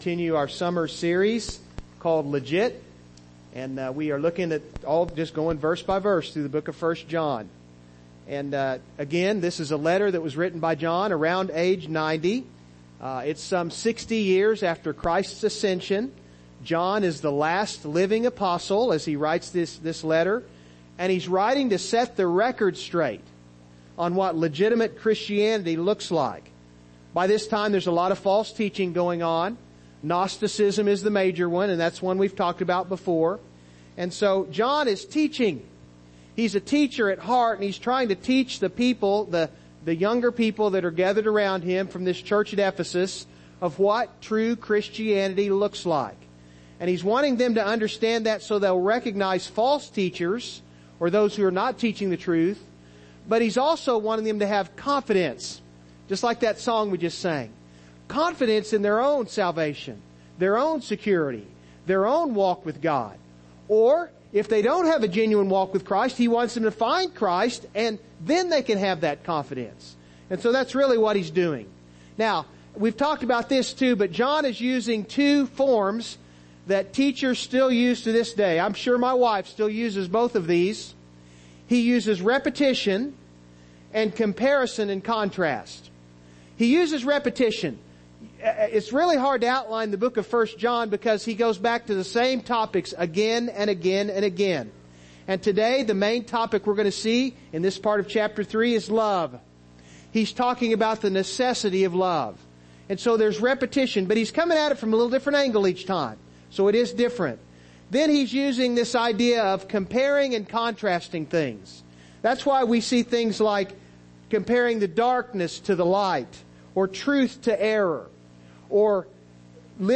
Studies in 1 John Service Type: Morning Service « Faith Legit